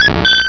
pokeemerald / sound / direct_sound_samples / cries / clefable.aif